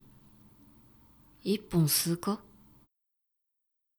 ボイス
ダウンロード 男性_「…1本吸うか？」
中性中音大人